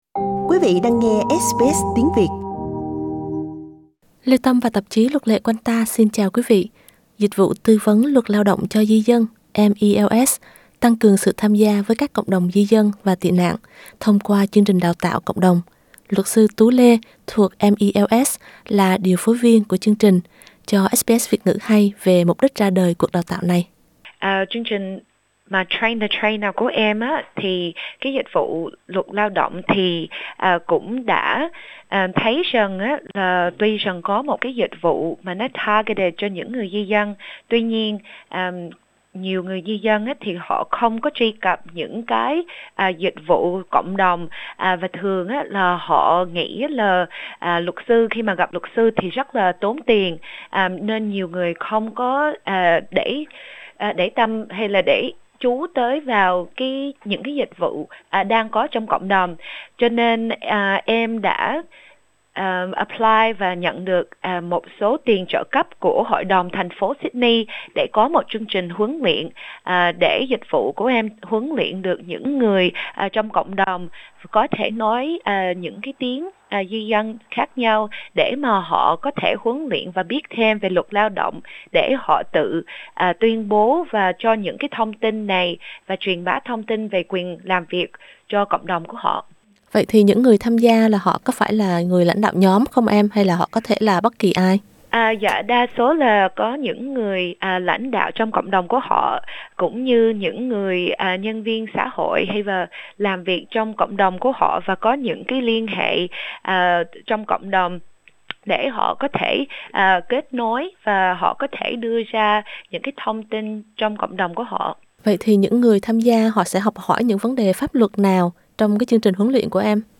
Mời quý vị bấm vào biểu tượng radio để nghe toàn bộ bài phỏng vấn.